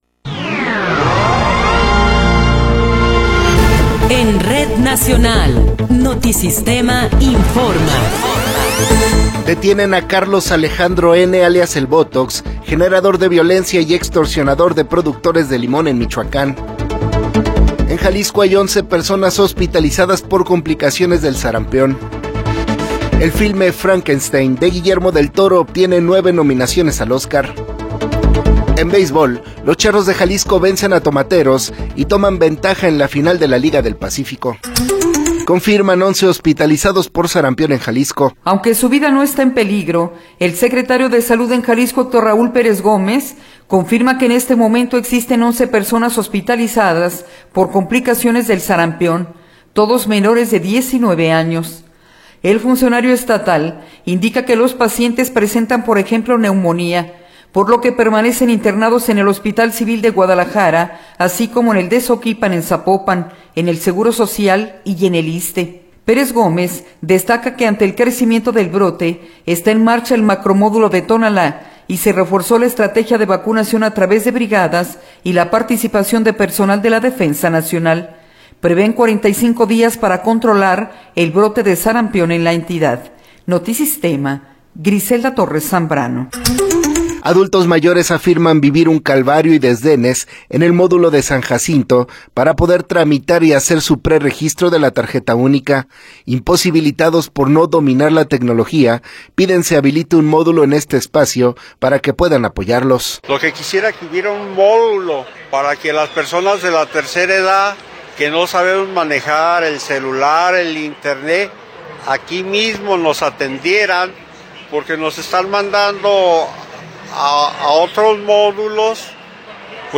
Noticiero 9 hrs. – 22 de Enero de 2026
Resumen informativo Notisistema, la mejor y más completa información cada hora en la hora.